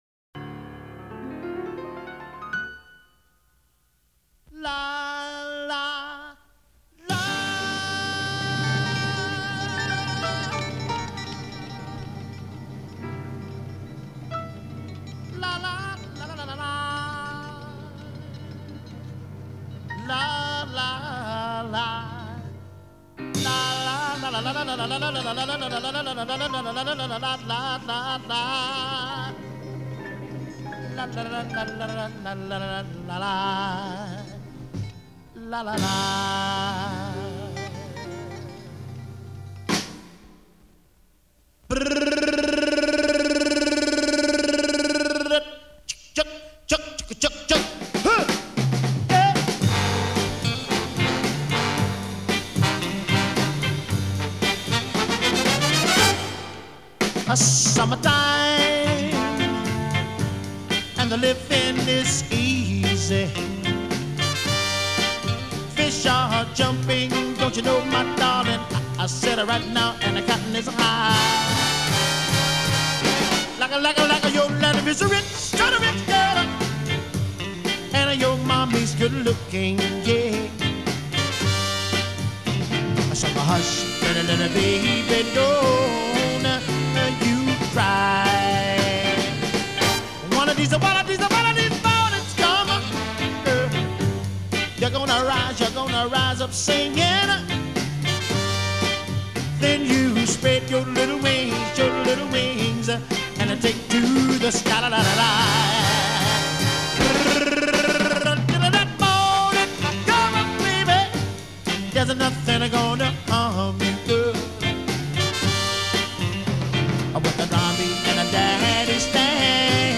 TEMPO : 112